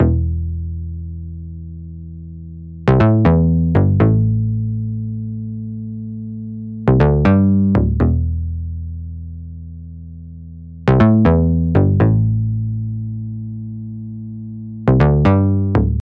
Или просто хорошо усажен в микс и работает с (этаким совсем минимальным) киком?